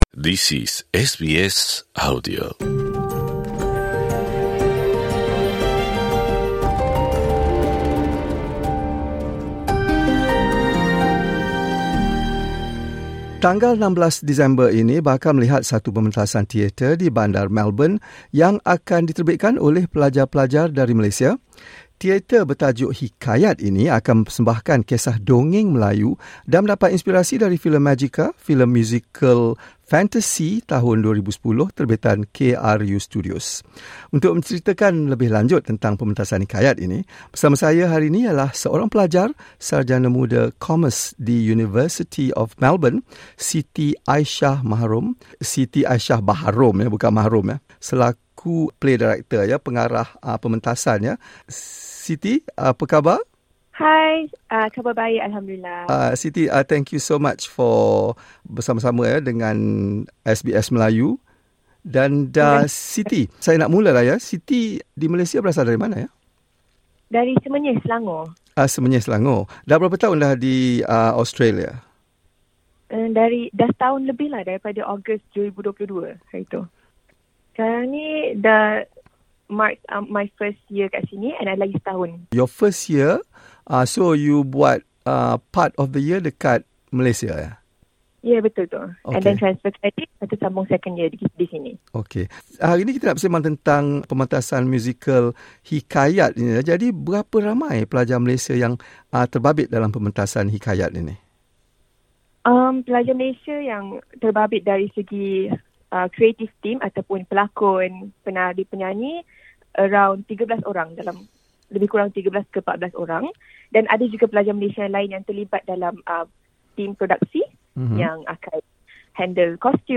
perbualan